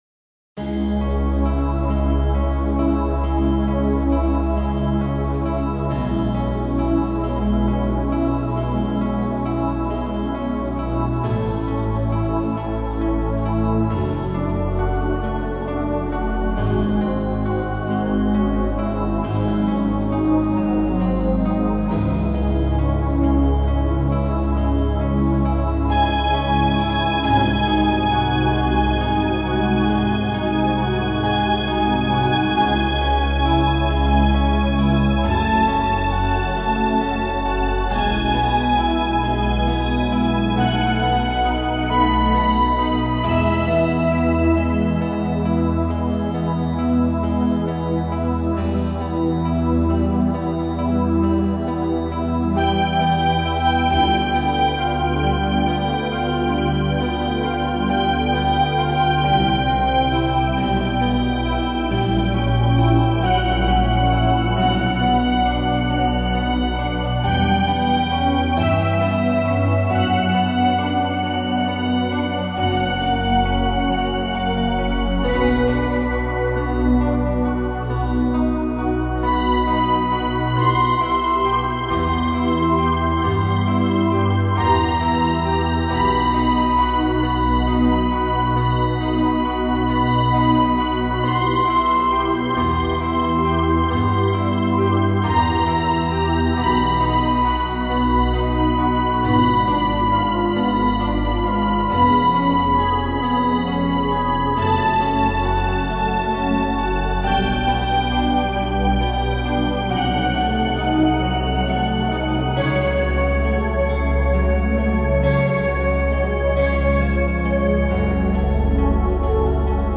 ＜注意＞ＭＰ３データはＳＣ８８５０で演奏したものです